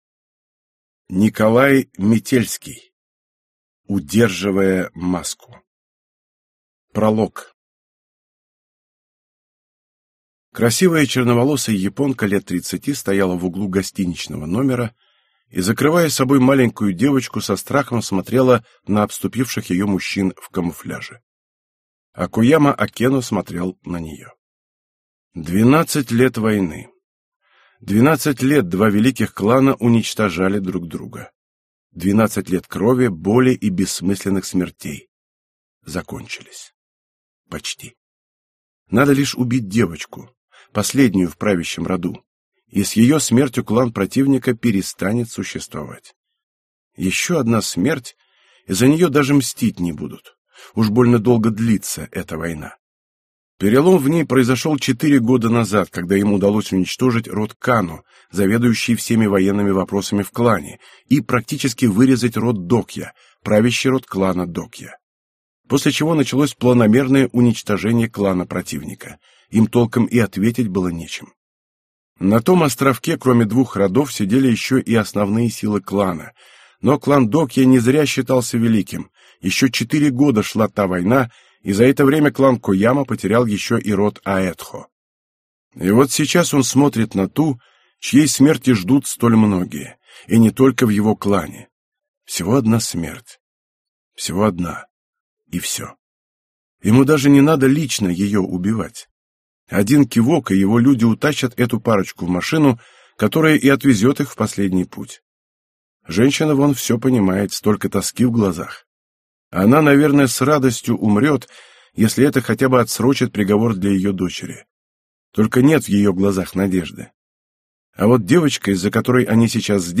Аудиокнига Удерживая маску | Библиотека аудиокниг